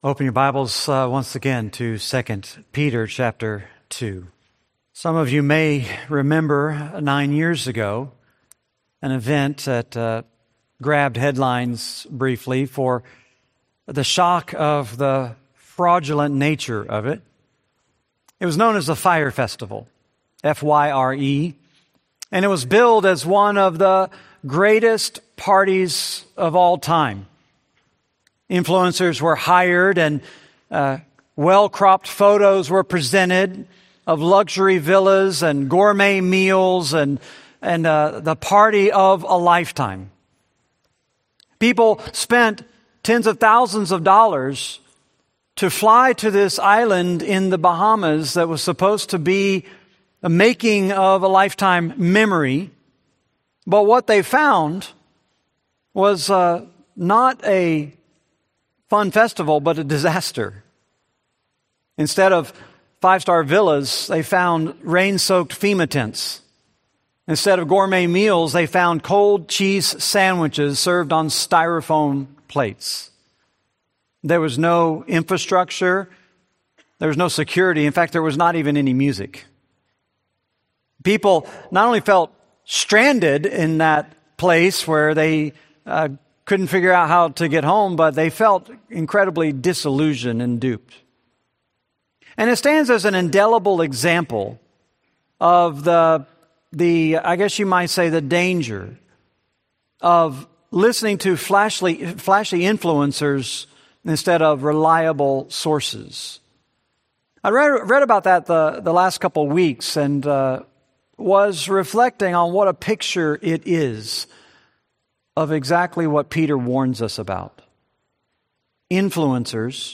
Series: 2 Peter, Sunday Sermons